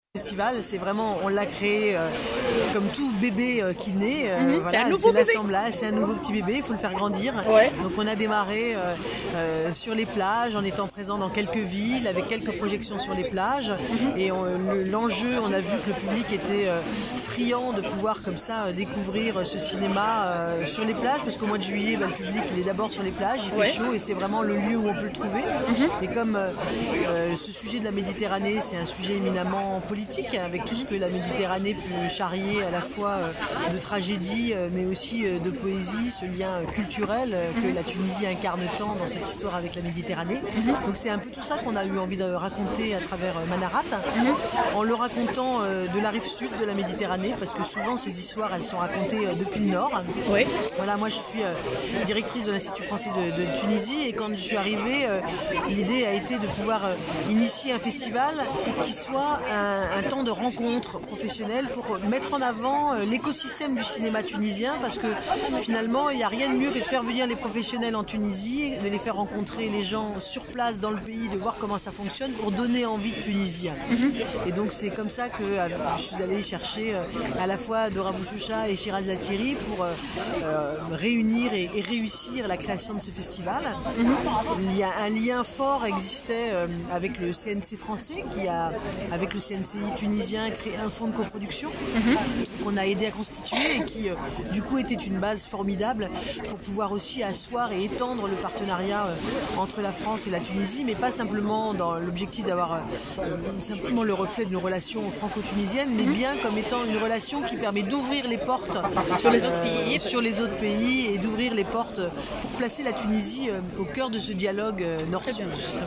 في ندوة صحفية انتظمت، مساء السبت الفارط في الجناح التونسي بمهرجان كان السينمائي